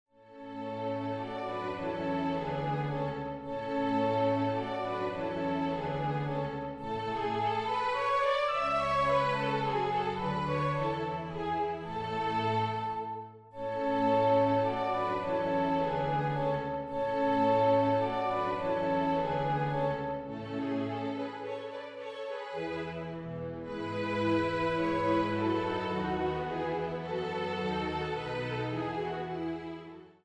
Singspiel
Arie des Bastienne Nr. 11
Orchester-Sound